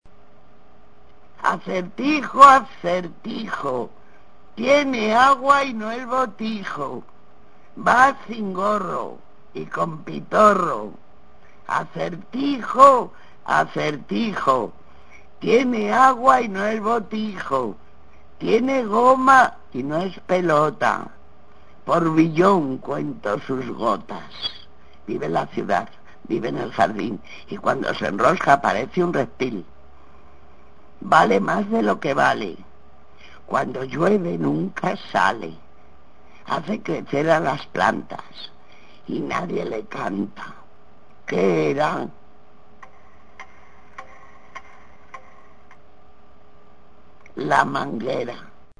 Hemos recuperado unas grabaciones antiguas donde Gloria Fuertes
recitaba varias de sus poesías.
acertijos y adivinanzas
gloria_fuertes_acertijo1.mp3